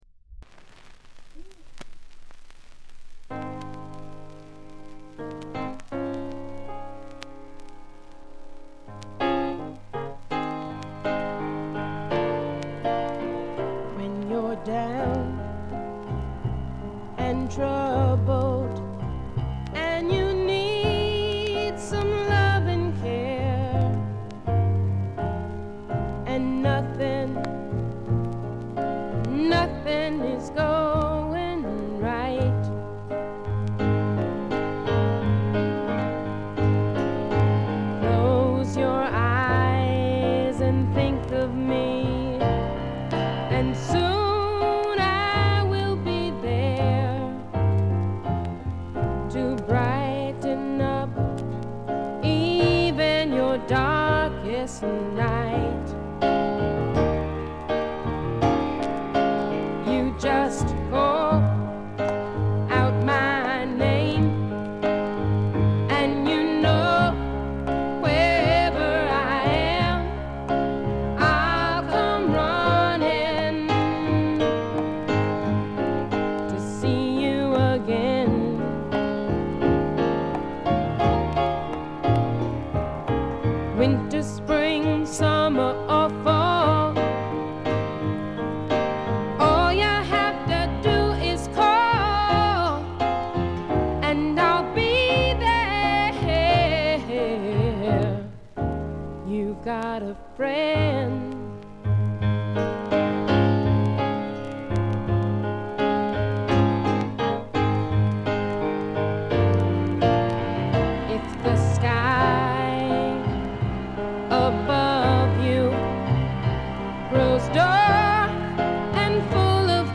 全体にバックグラウンドノイズ、チリプチ多め大きめ。
試聴曲は現品からの取り込み音源です。